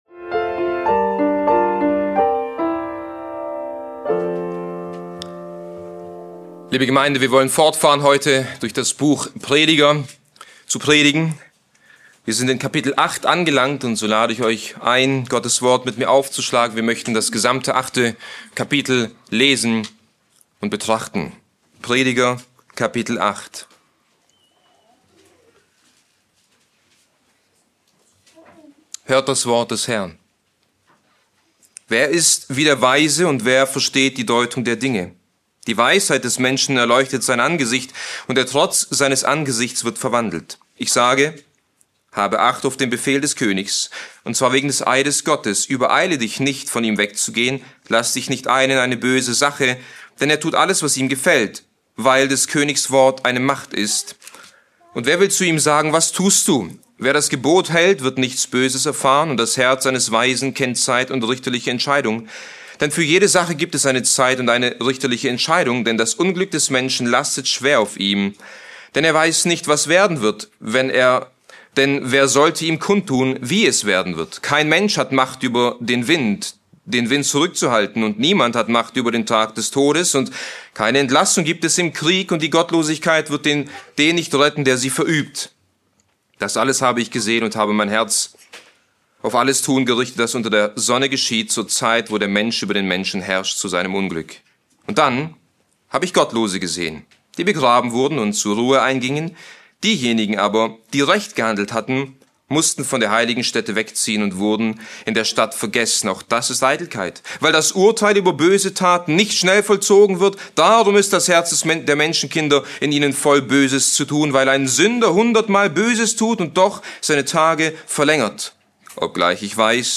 ## Details Diese Predigt behandelt Prediger 8 und zeigt auf, wie weise Christen in einer gefallenen Welt leben sollen....